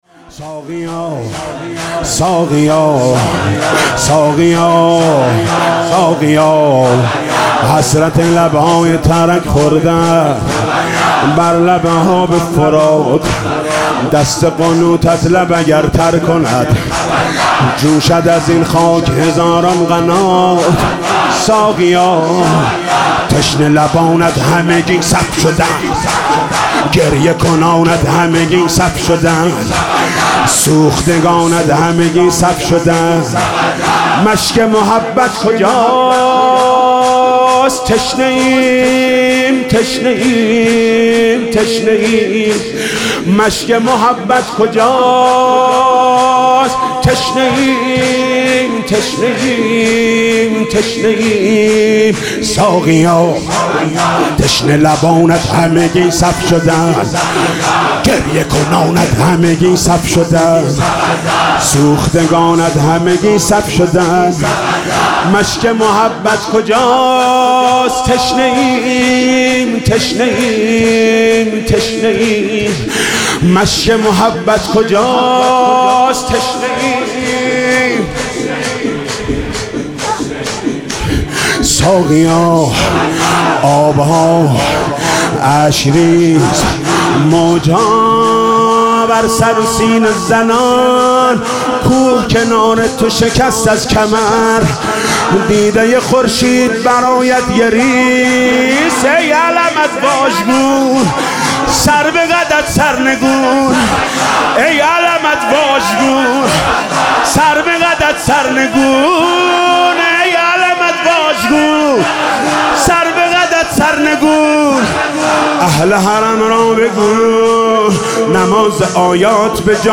شور: ساقیا حسرت لبهای ترک خورده ات